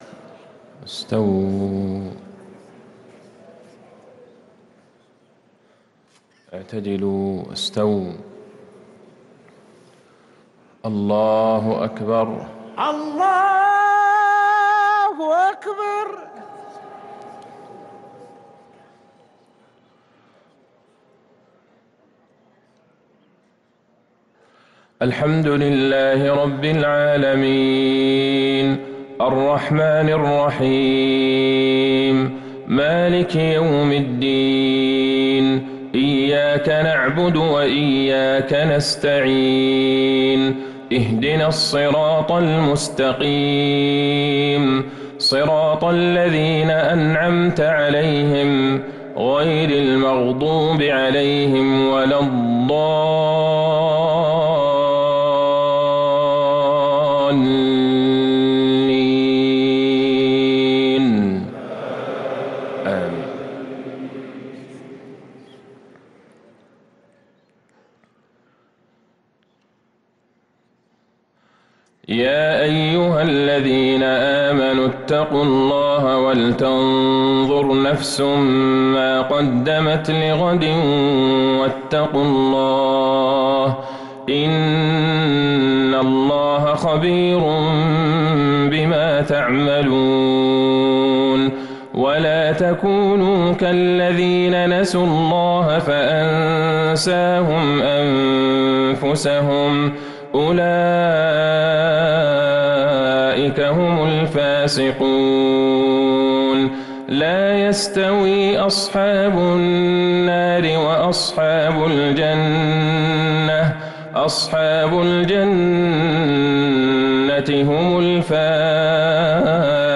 صلاة العشاء للقارئ عبدالله البعيجان 23 شعبان 1444 هـ
تِلَاوَات الْحَرَمَيْن .